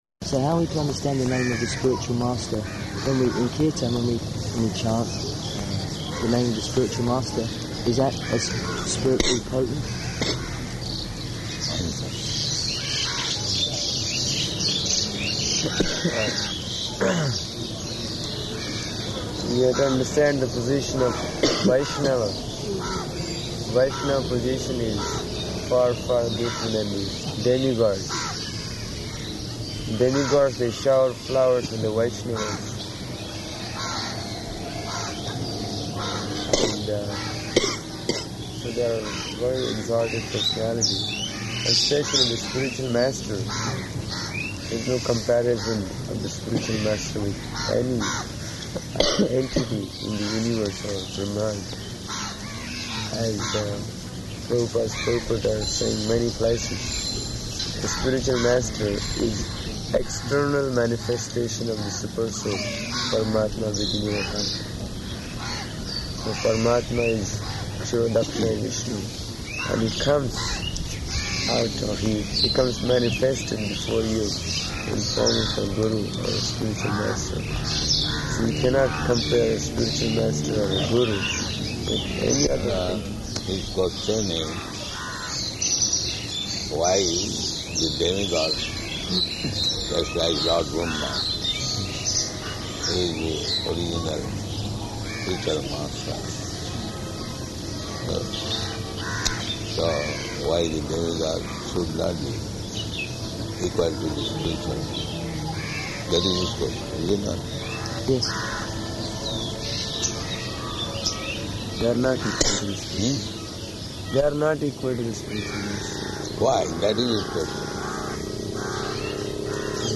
Morning Walk [partially recorded]
Type: Walk
Location: Delhi